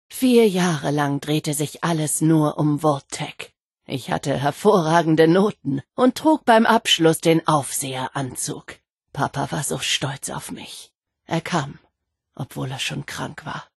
Vault-76-Aufseherin_0003d25c_1.ogg (OGG-Mediendatei, Dateigröße: 103 KB.
Wastelanders: Audiodialoge